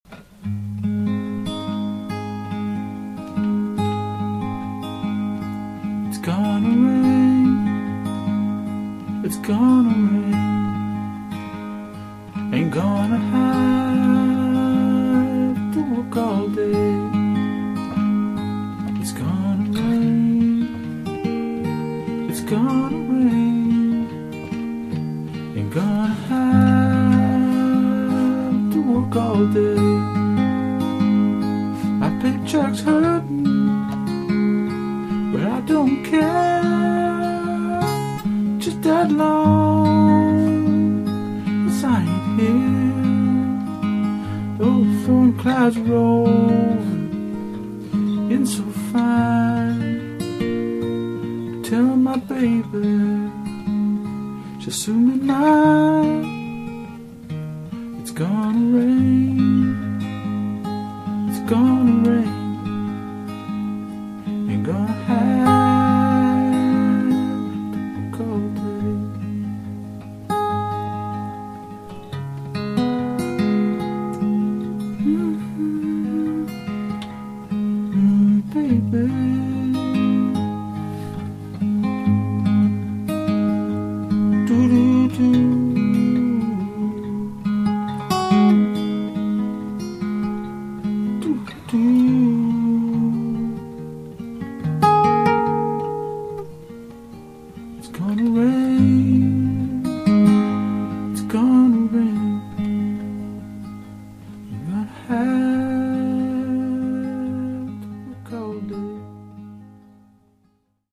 with uke